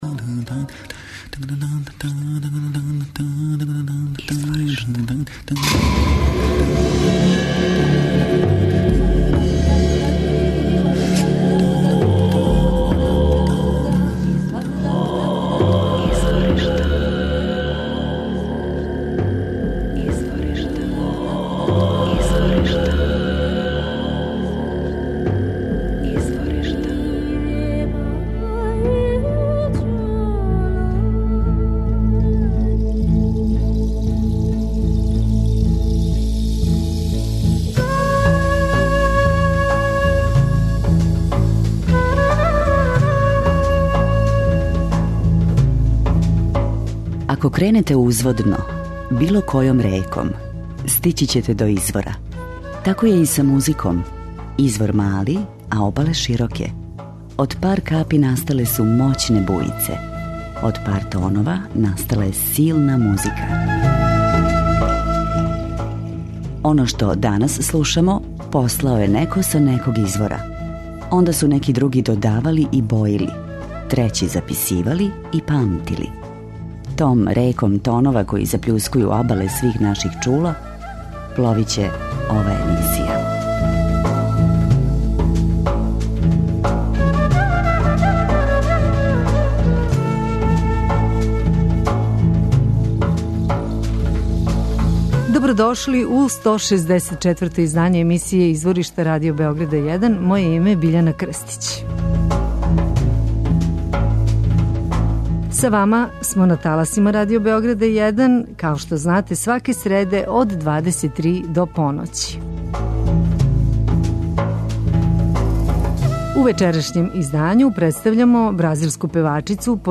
позната поп-рок певачица